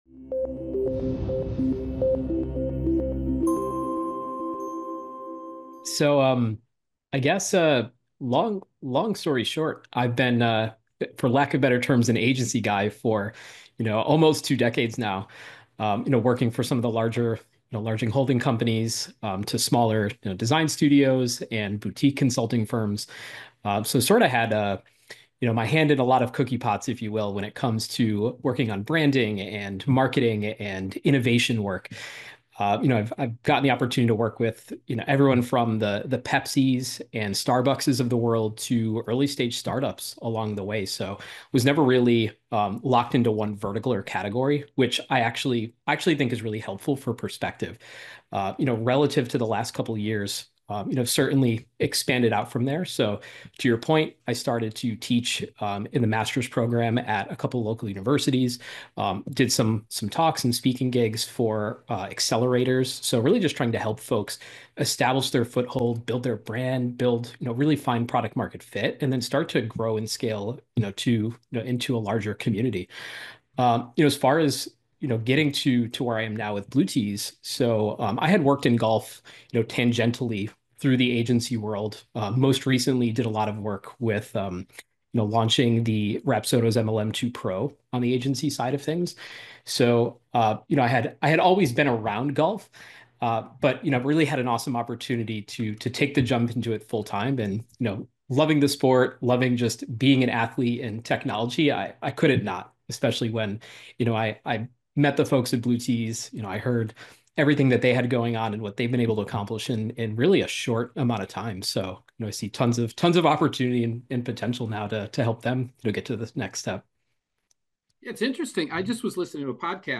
The Chicago Golf Report Podcast brings the best of golf in Chicago to you featuring exclusive interviews with some of top newsmakers in the Chicagoland golf community.